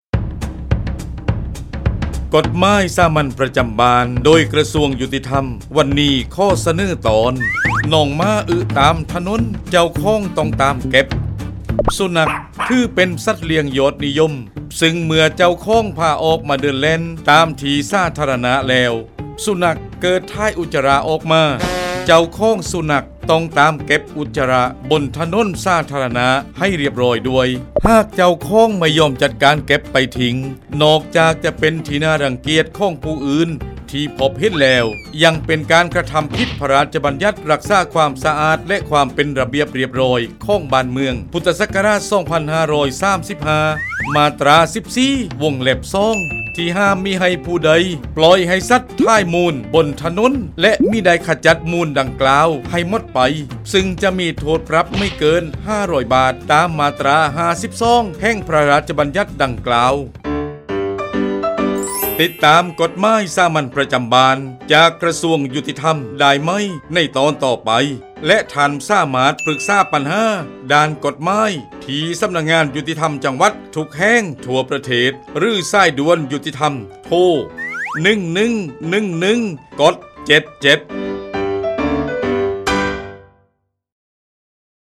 กฎหมายสามัญประจำบ้าน ฉบับภาษาท้องถิ่น ภาคใต้ ตอนน้องหมาอึตามถนน เจ้าของต้องตาม
ลักษณะของสื่อ :   คลิปเสียง, บรรยาย